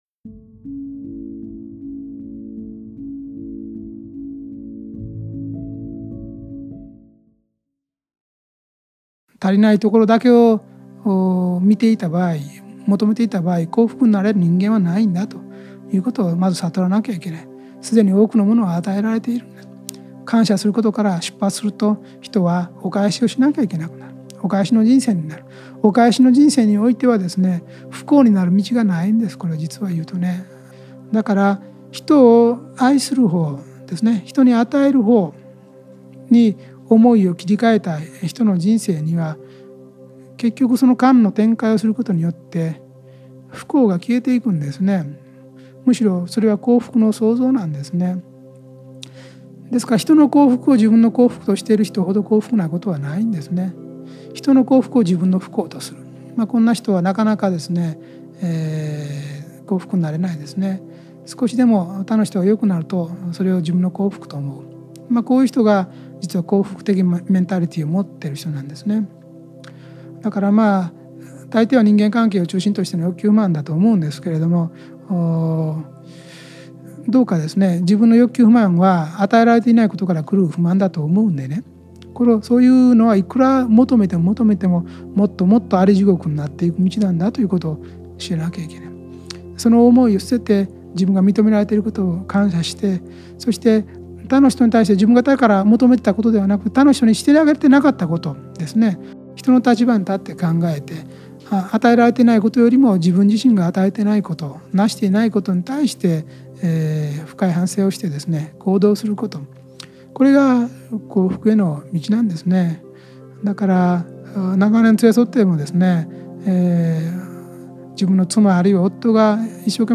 ラジオ番組「天使のモーニングコール」で過去に放送された、幸福の科学 大川隆法総裁の説法集です。
大川隆法総裁御法話 「人間を幸福にする四つの原理」より